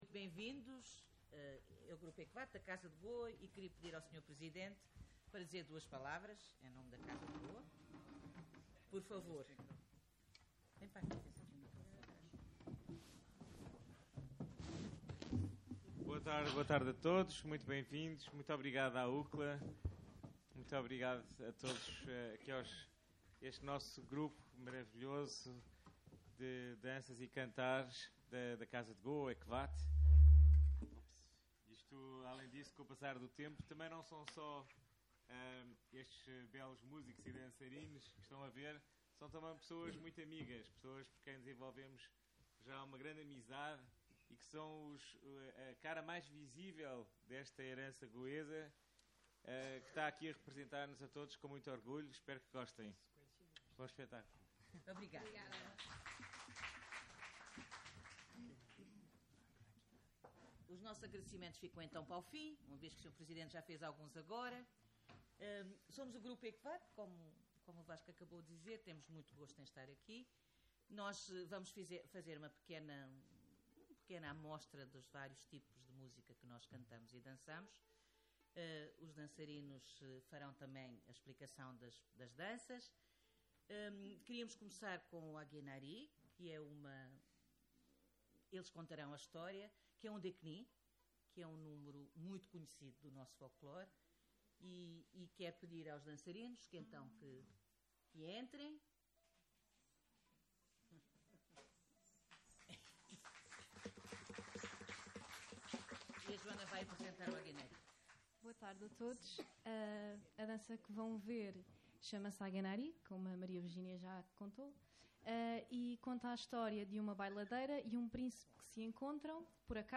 Divulgar a música e as danças de Goa, desafiando todos a descobrirem a sua cultura ancestral, foi o propósito da atuação do Grupo EKVAT no dia 17 de novembro, no auditório da UCCLA.
Entre o seu reportório contam-se canções e danças populares, como o Fugddi e o Deknni, bem como o Mandó, considerado o ex-libris da música de Goa, acompanhado de dulpodam.